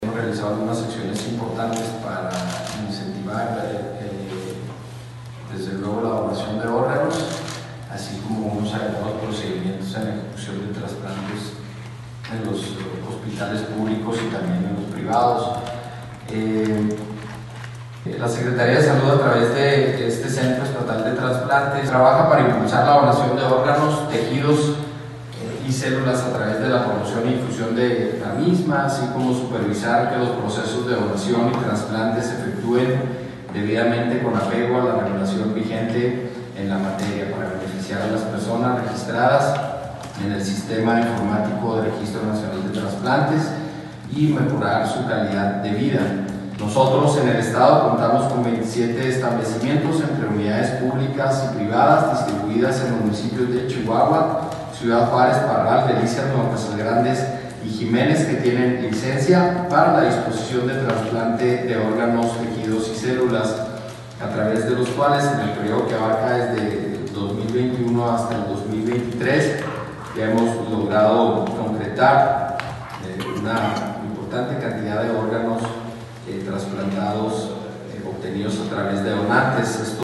AUDIO: GILBERTO BAEZA MENDOZA, TITULAR DE LA SECRETARÍA DE SALUD DEL ESTADO